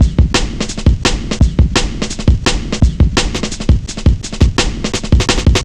Index of /90_sSampleCDs/Zero-G - Total Drum Bass/Drumloops - 3/track 58 (170bpm)